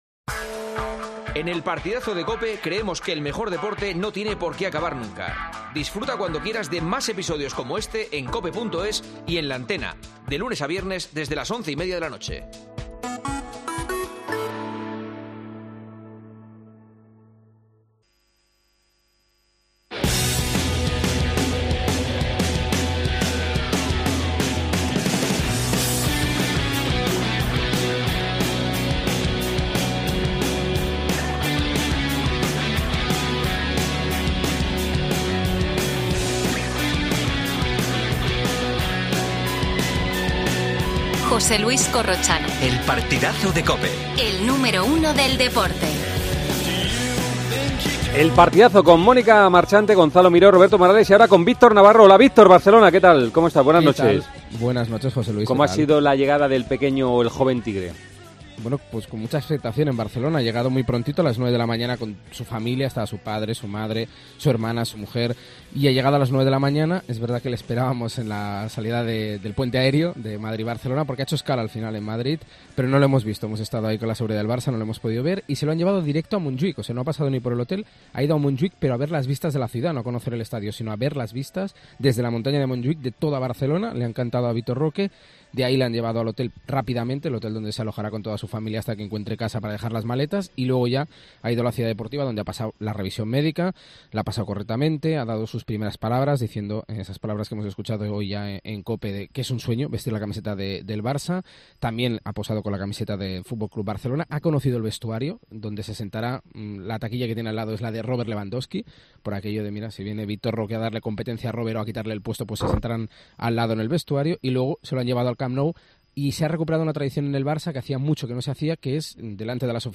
¿Cómo ha sido el recibimiento a Vitor Roque?. Entrevista